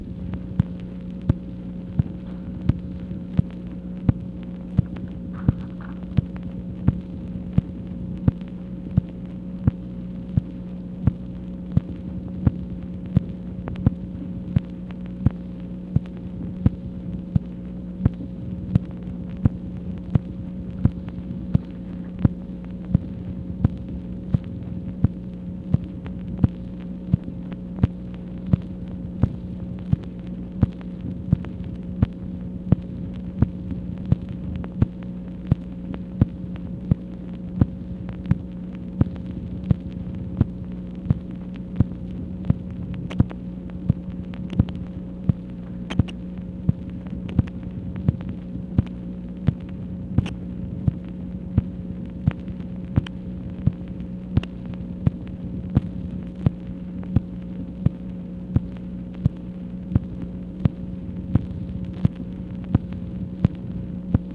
MACHINE NOISE, June 9, 1966